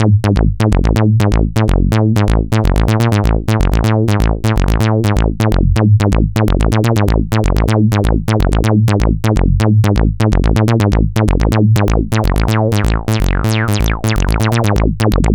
cch_acid_series_125.wav